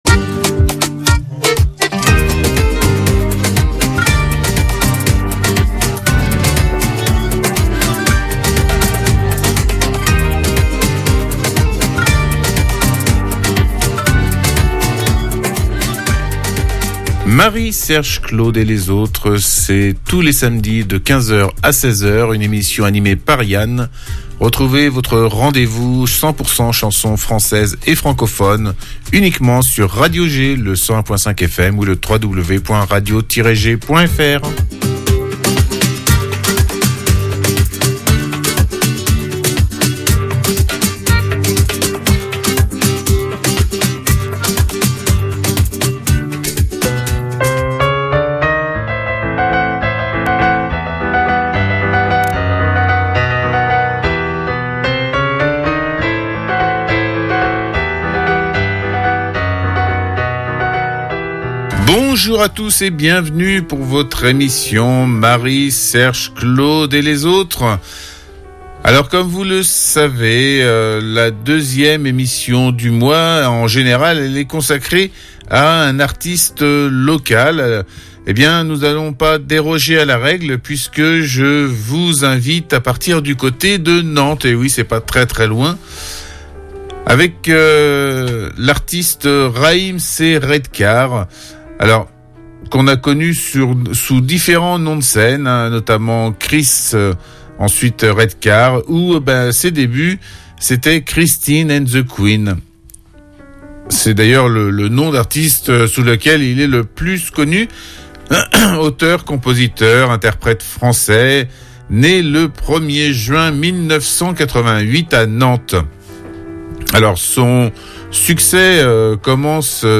une heure de chansons françaises